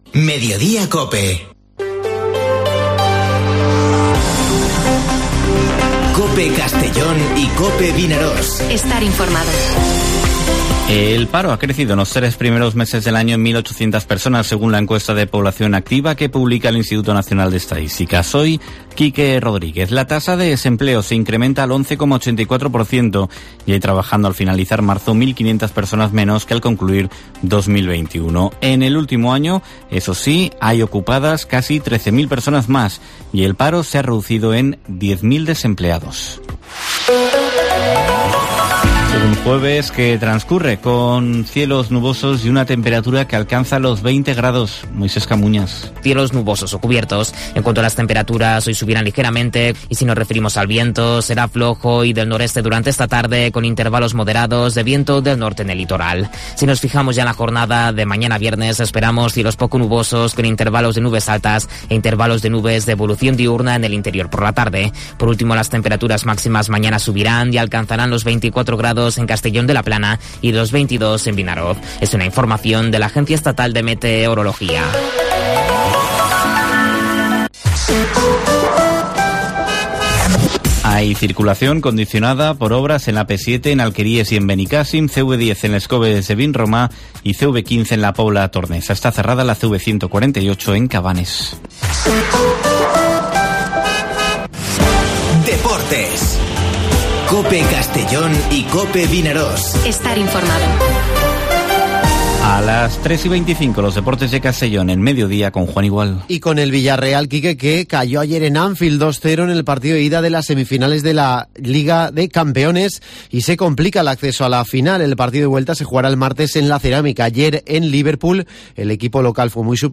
Informativo Mediodía COPE en la provincia de Castellón (28/04/2022)